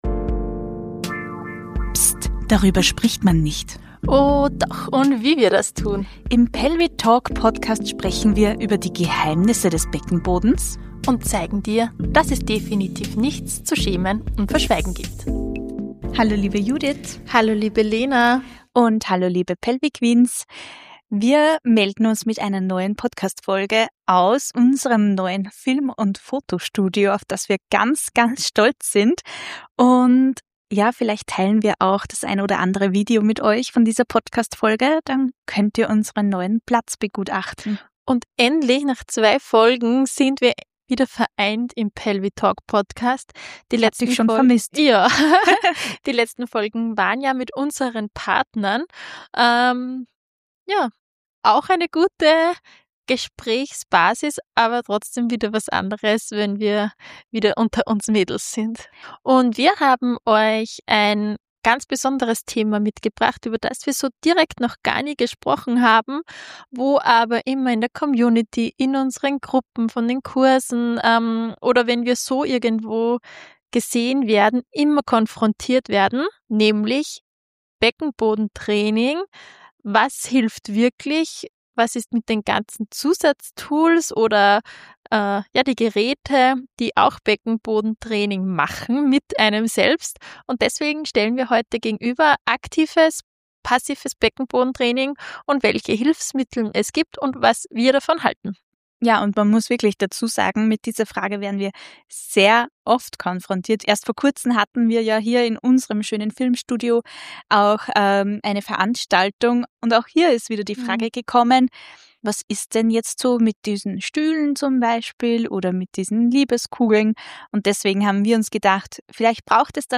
Ein ehrliches Gespräch über aktives vs. passives Beckenbodentraining – und was wirklich dahintersteckt.